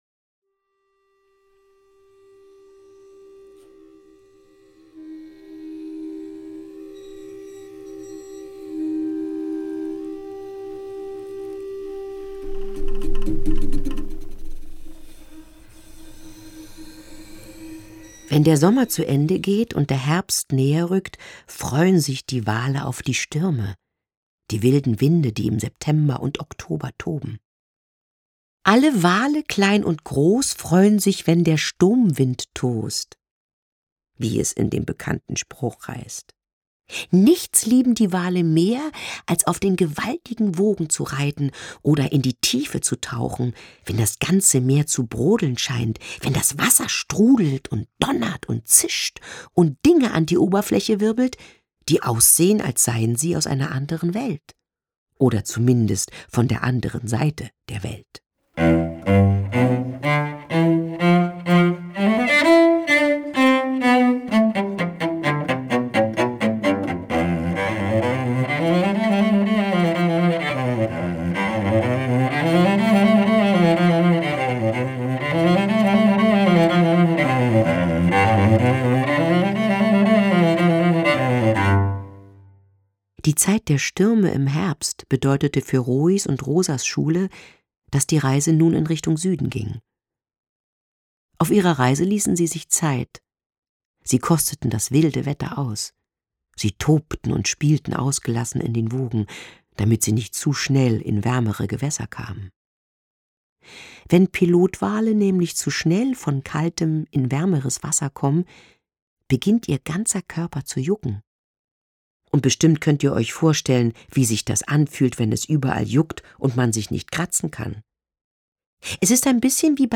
Lesung mit Musik
Mitarbeit Sprecher: Dagmar Manzel